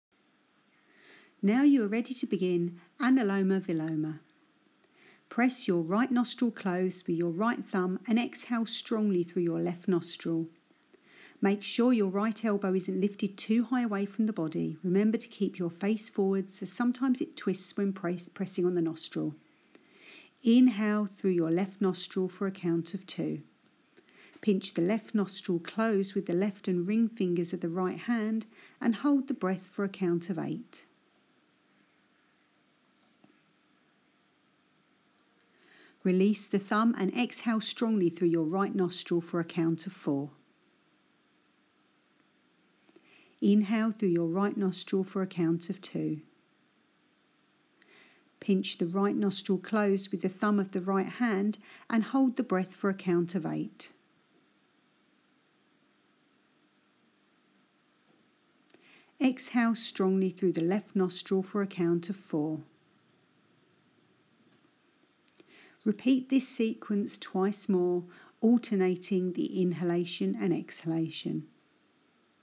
anuloma-viloma-audio.mp3